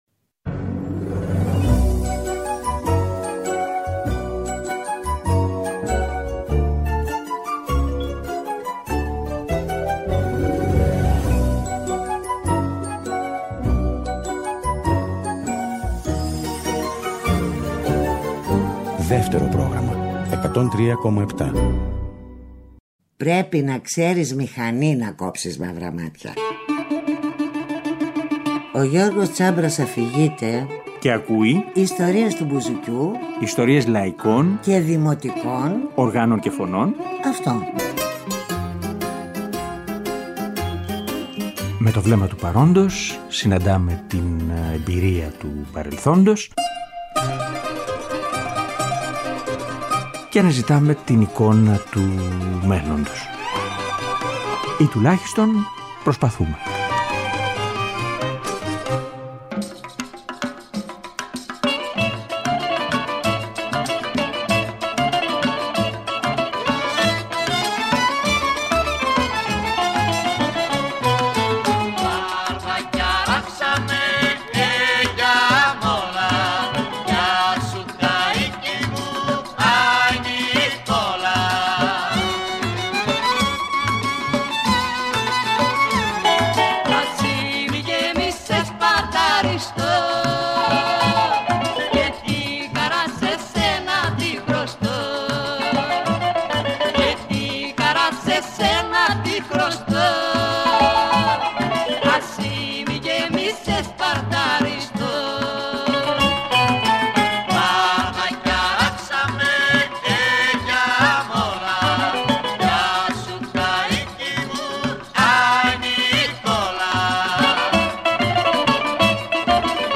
Ξεχωριστό κομμάτι, οι αυτοβιογραφικές αφηγήσεις και οι επιλογές από τα ακούσματά του…